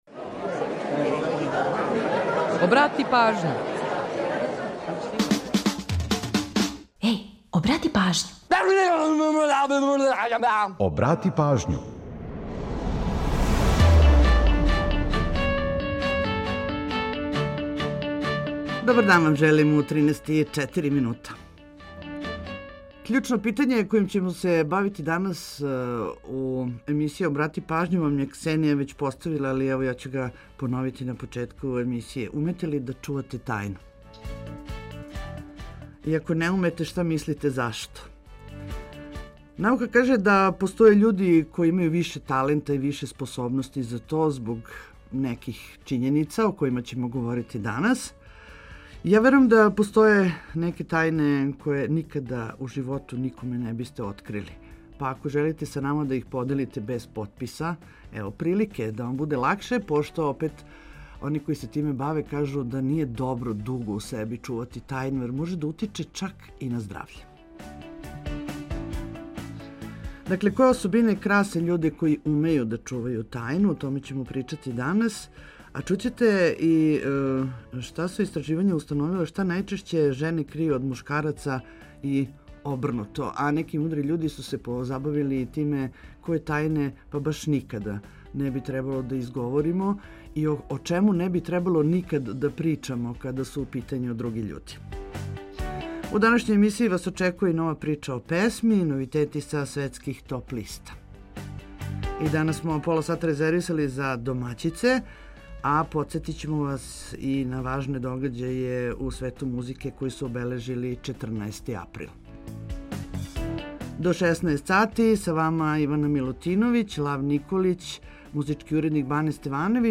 Очекује вас још једна прича о песми, новитети са светских топ листа и пола сата резервисаних за домаћу музику.